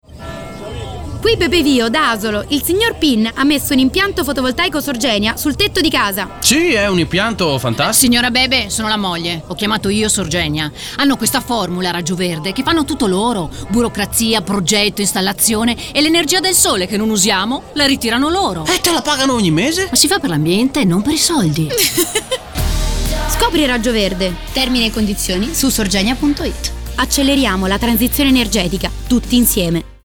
Sorgenia: on air la campagna radio dedicata al FV residenziale
Protagonista della campagna radio, che sarà trasmessa sulle principali emittenti nazionali, è Bebe Vio.
Ogni spot della durata di 30 secondi si conclude con la voce di Bebe Vio e lo slogan “Acceleriamo la transizione energetica, tutti insieme”.